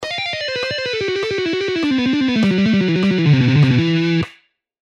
Legato Guitar Exercise
Lessons-Guitar-Mark-Tremonti-Legato-Exercises-2.mp3